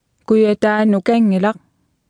Below you can try out the text-to-speech system Martha.
Speech Synthesis Martha